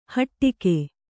♪ haṭṭike